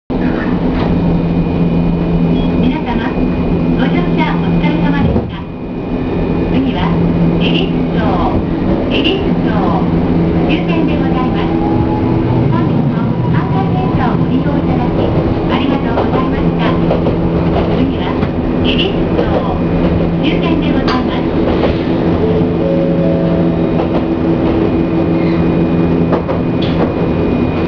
・車内アナウンス
モ601形で収録した、と言うだけで別にどの車両でも放送自体は変わりません。最後の３打点は一応、車内チャイム的な扱いなのでしょう。